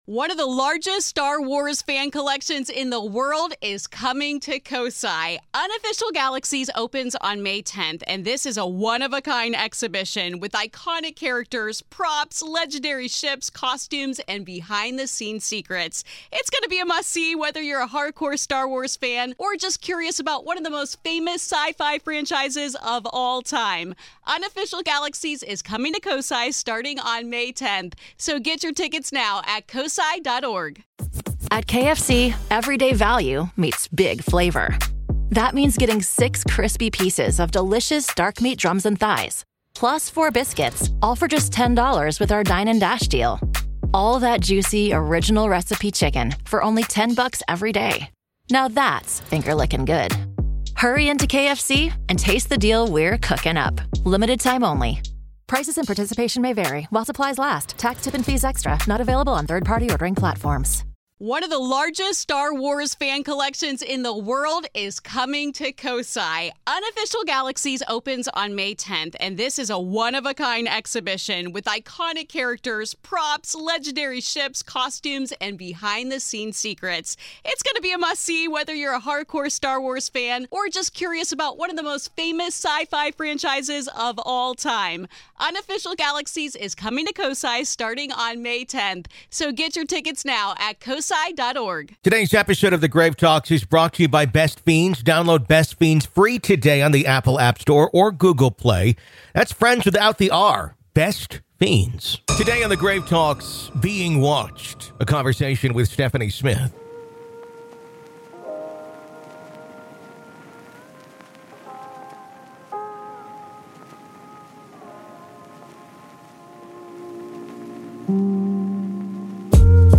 Being Watched | A Conversation